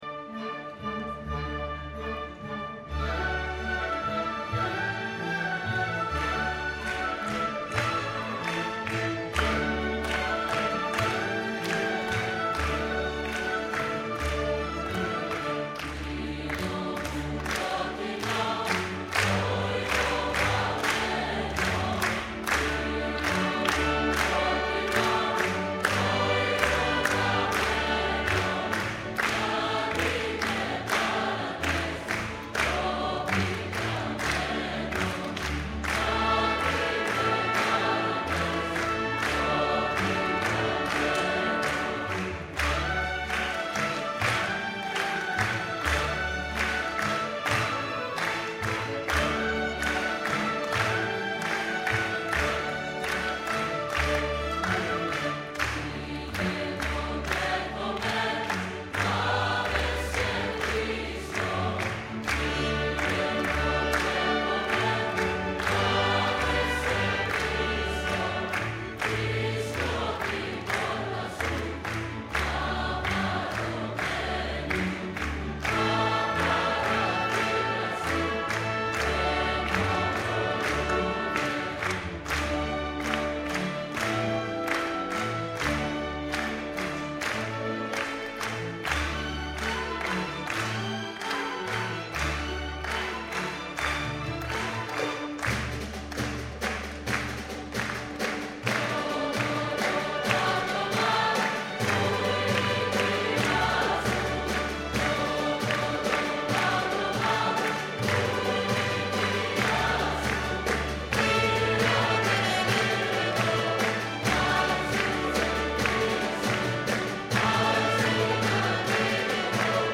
“Μήλο μου κόκκινο” παραδοσιακό Μακεδονίας
για Χορωδία και Ορχήστρα (live)
(από την Εκπαιδευτική Συναυλία “Μια φορά η Μουσική”)
Συμφωνική Ορχήστρα Κύπρου Χορωδία Λυκείου Λευκωσίας